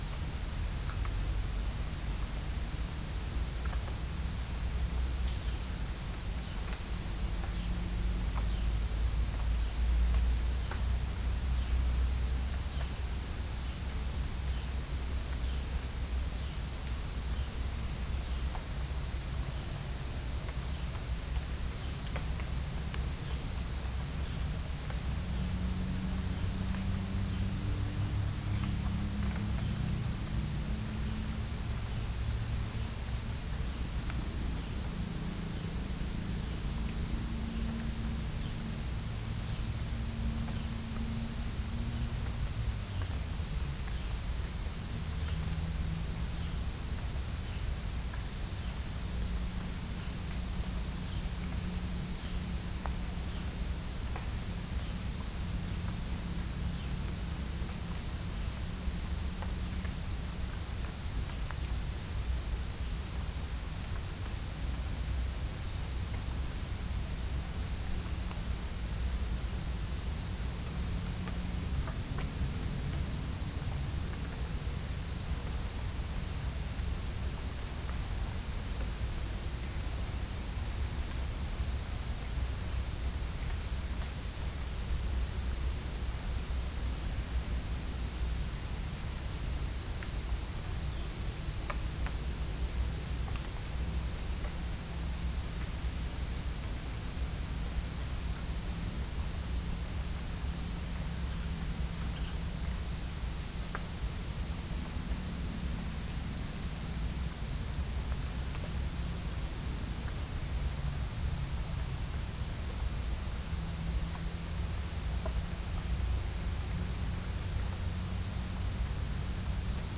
Chalcophaps indica
Pycnonotus goiavier
Pycnonotus aurigaster
Halcyon smyrnensis
Orthotomus ruficeps
Todiramphus chloris
Dicaeum trigonostigma